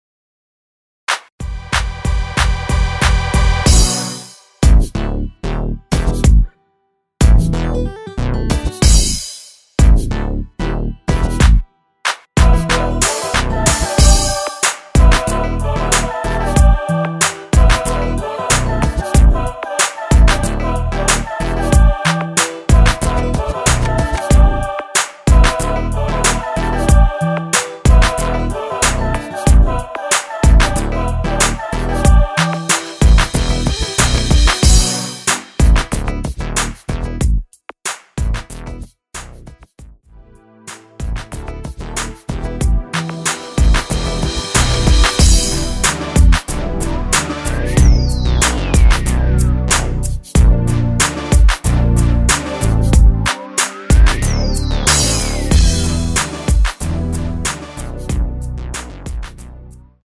Em
앞부분30초, 뒷부분30초씩 편집해서 올려 드리고 있습니다.